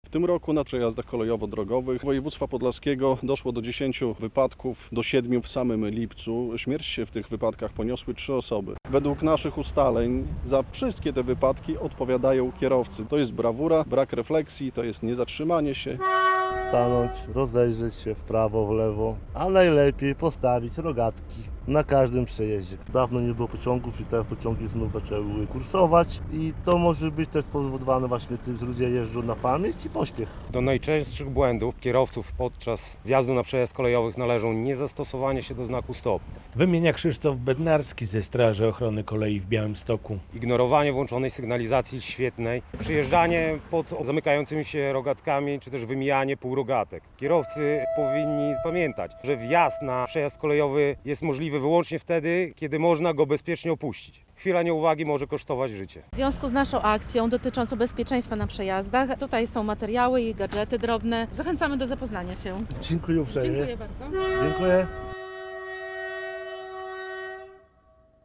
Akcja "Bezpieczny Przejazd" w Śniadowie - relacja